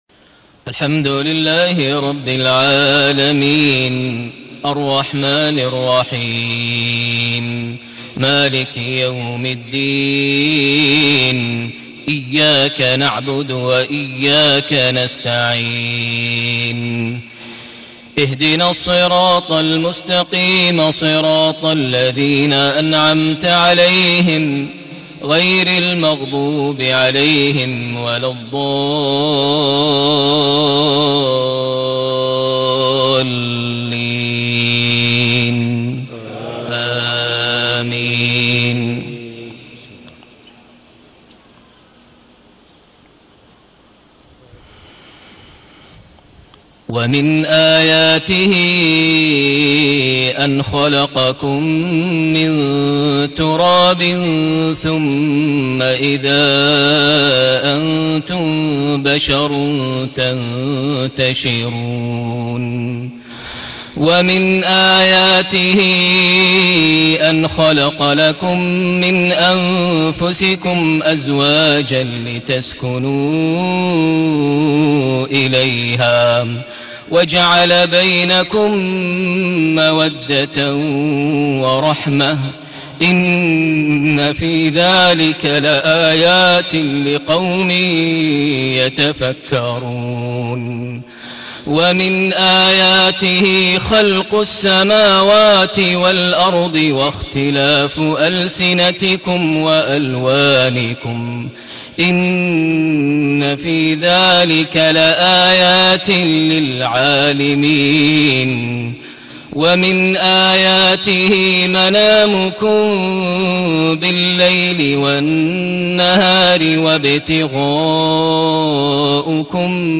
صلاة المغرب ١١جماد الآخر ١٤٣٨هـ سورة الروم ٢٠-٢٧ > 1438 هـ > الفروض - تلاوات ماهر المعيقلي